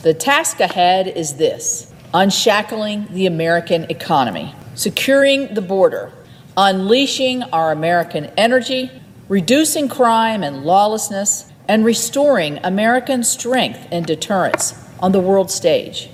West Virginia Senator Shelly Moore-Capito spoke on the floor of the U.S. Senate to highlight issues the new Republican majority will address in the second Trump Administration.